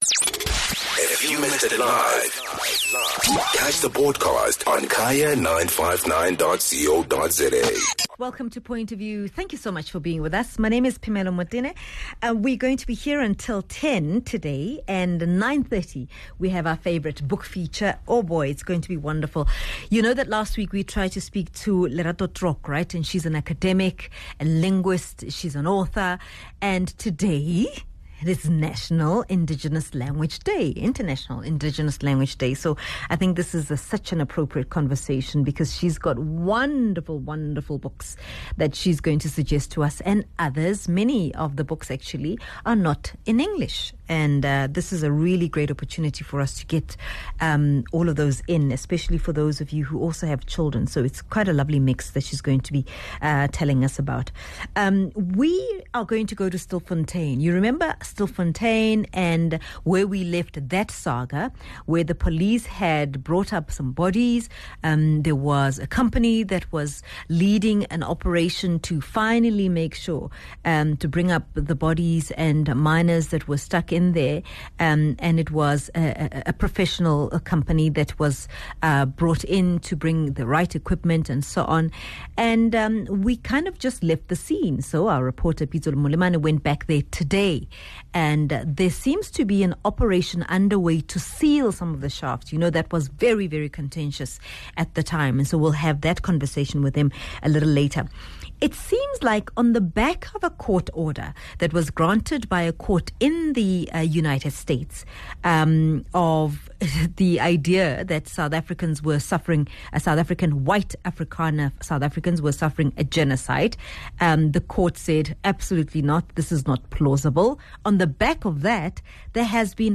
Dr. Elias Sithole - Head of the National Disaster Management Centre